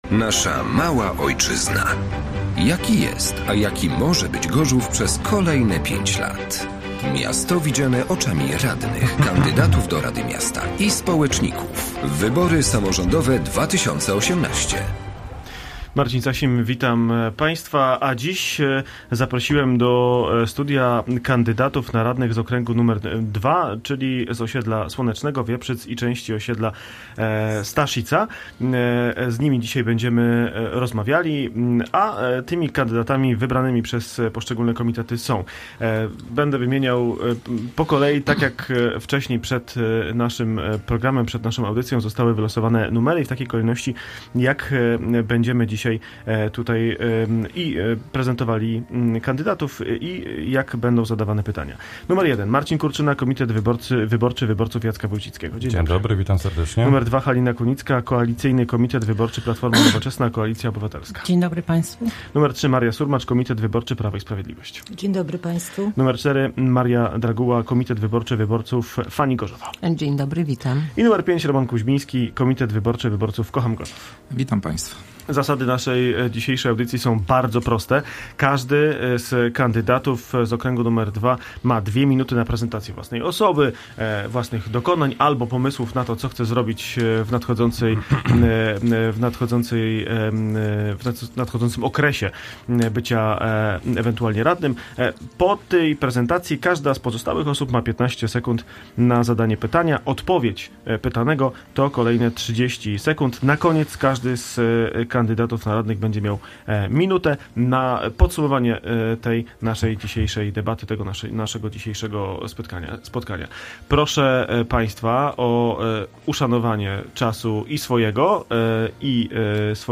Samorząd nasza Mała Ojczyzna DEBATA kandydatów na radnych z okręgu wyborczego nr 2